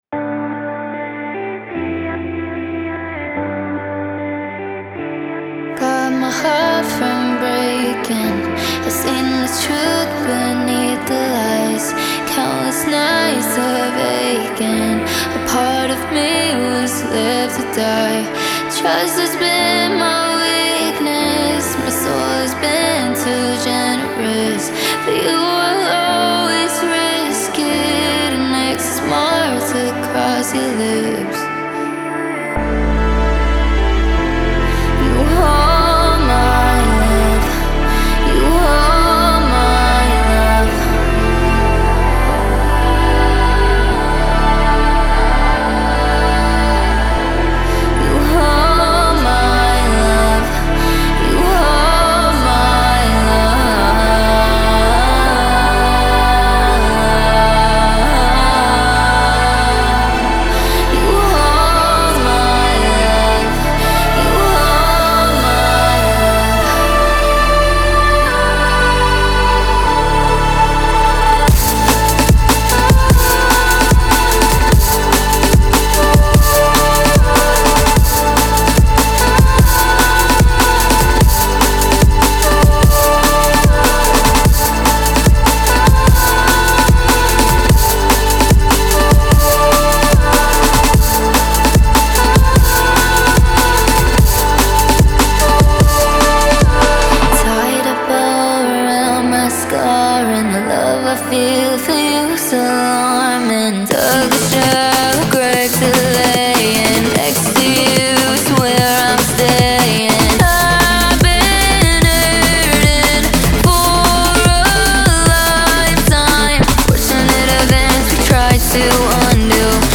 • Жанр: Музика, Alternative